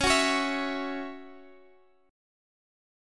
Listen to C#mbb5 strummed